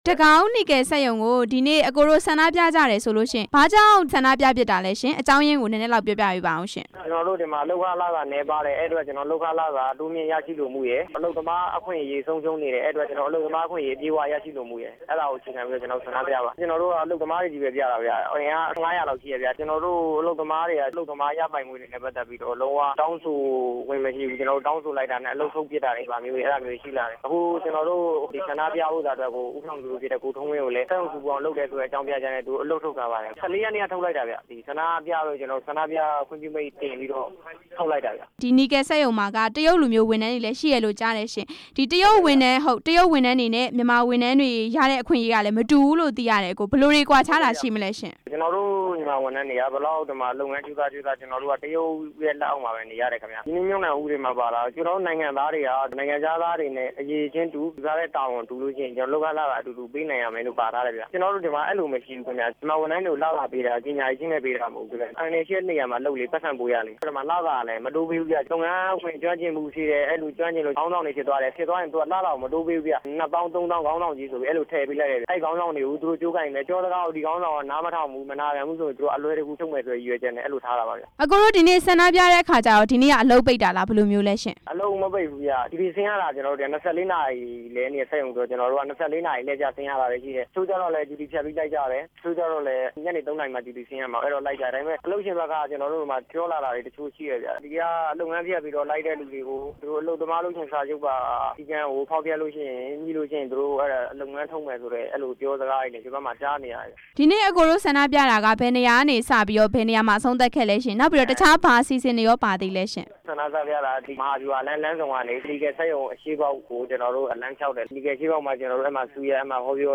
တကောင်းနီကယ် စက်ရုံအလုပ်သမားတွေ ဆန္ဒပြတဲ့အကြောင်း မေးမြန်းချက်